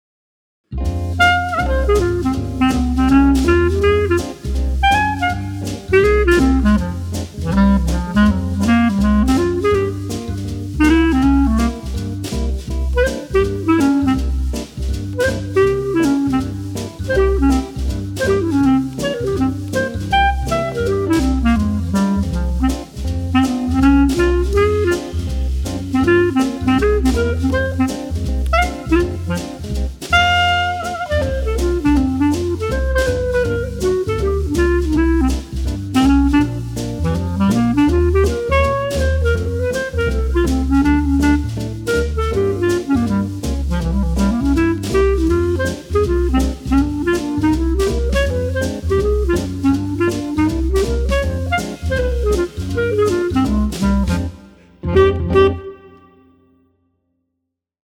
46 Jazz Studies for Clarinet over three graded books